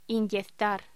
Locución: Inyectar
voz